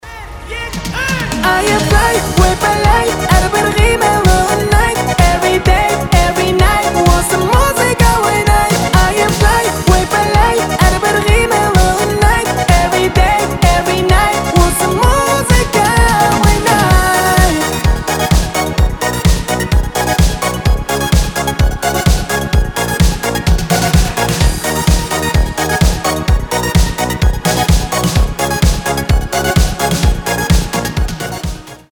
• Качество: 320, Stereo
позитивные
зажигательные
диско
казахские